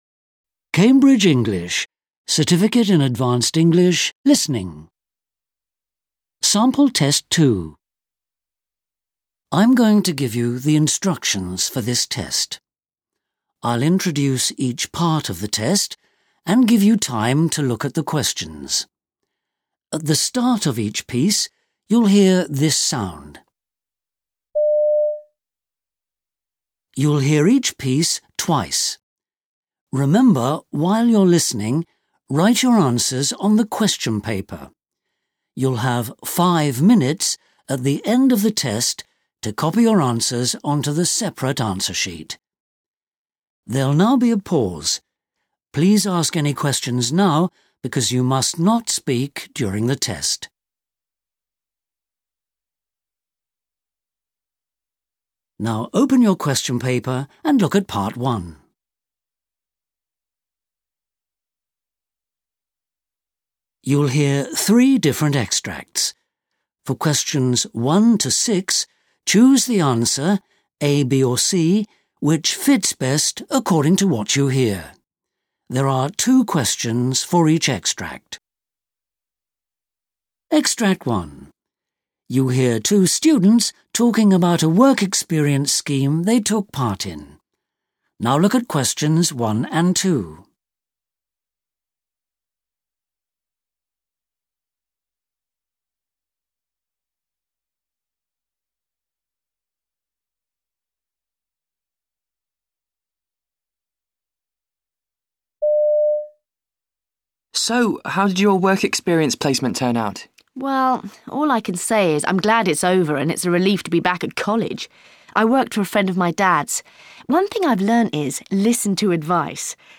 Listening test
You hear two students talking about a work-experience scheme they took part in.
You hear part of a discussion programme in which two journalists are talking about the world’s wetlands.
You overhear a woman showing a friend one of her childhood photos.